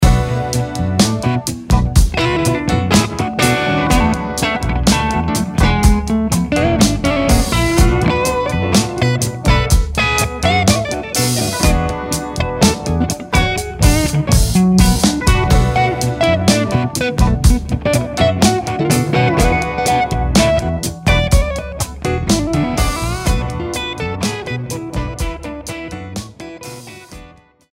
Many backing tracks are available individually at 99 cents.